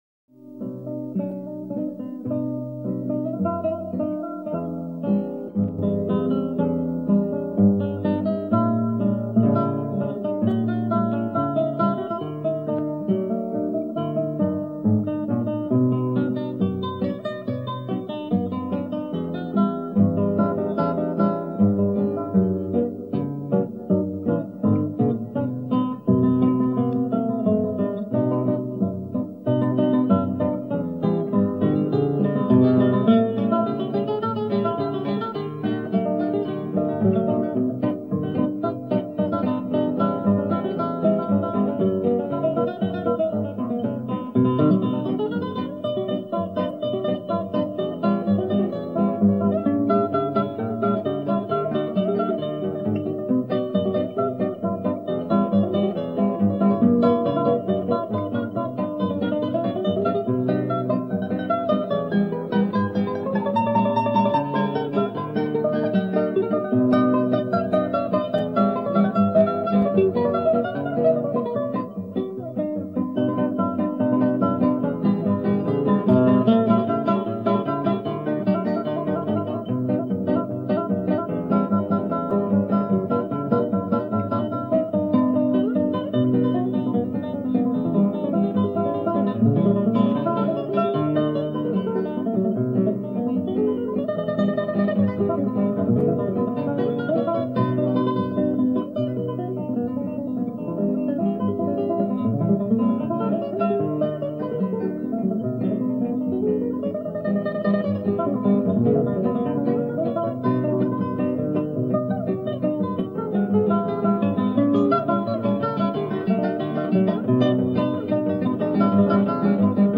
великолепный гитарист. К сожалению, это шумы с пластинки.